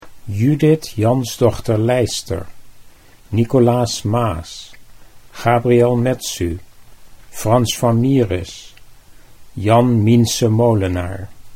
How to Pronounce the Names of Some Dutch Painting Masters